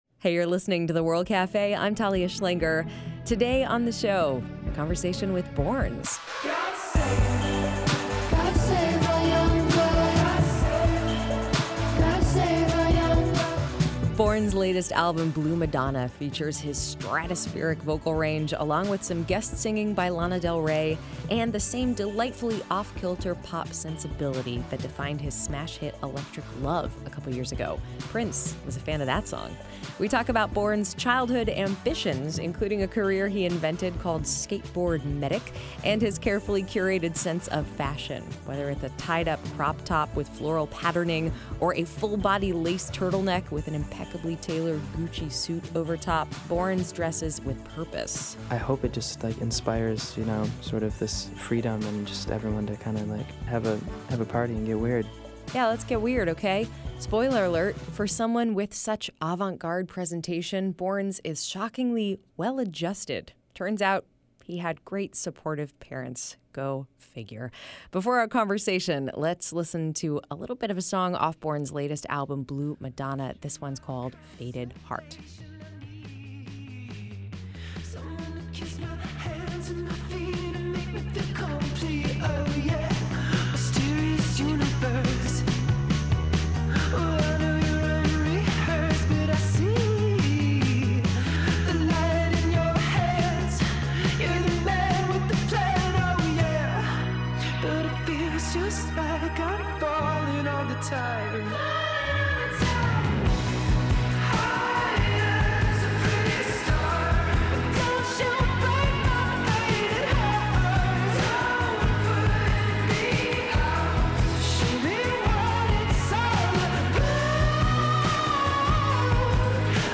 The avant-garde electro-pop artist talks about getting praise from Prince and working with Lana Del Rey.